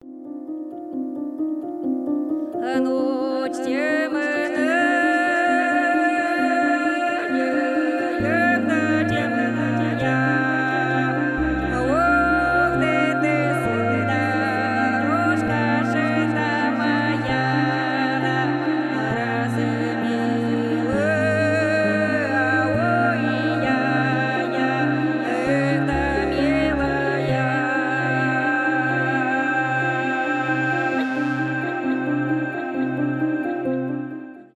неофолк